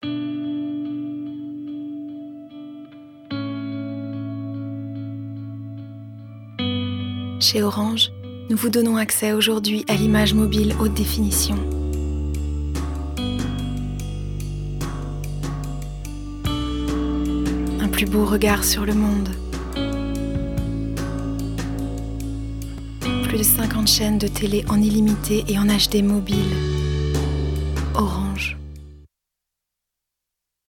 Voix off
- Mezzo-soprano